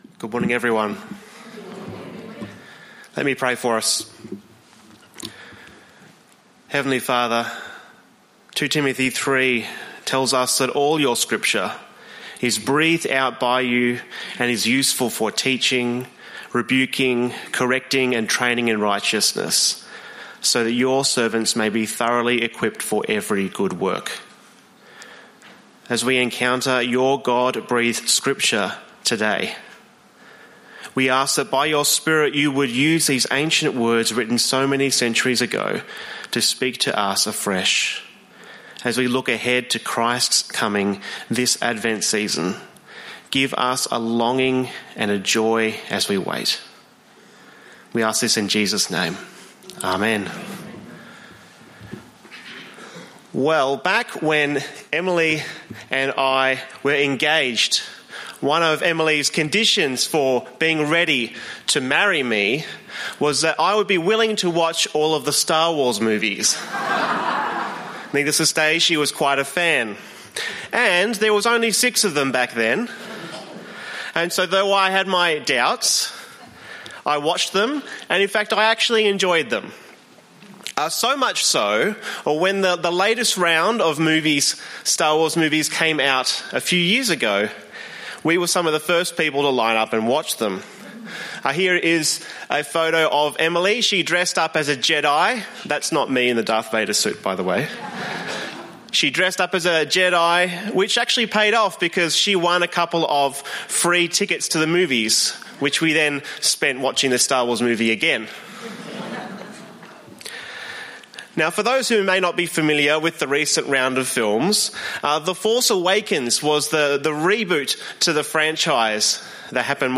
Download Download Bible Passage 2 Kings 21:1-18 In this sermon